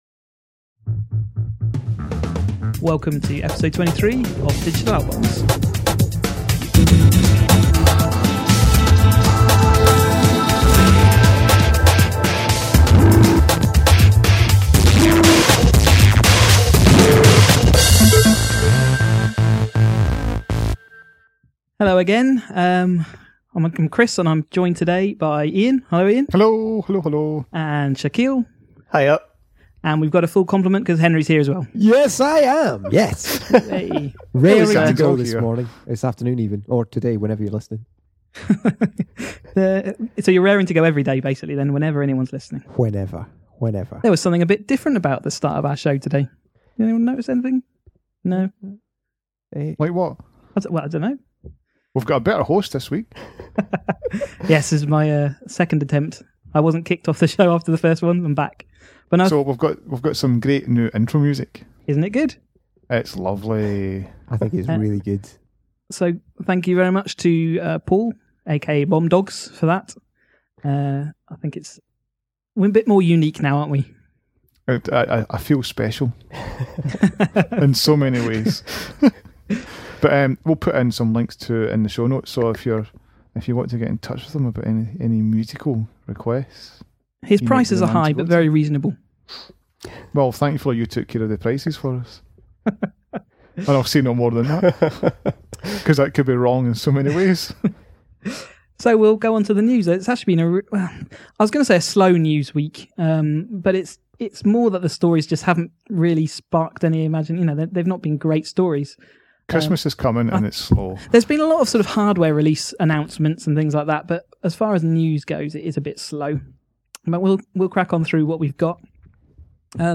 DigitalOutbox Episode 23 In this episode the team discuss …not much really. However, new title music is fabby and our first listener review!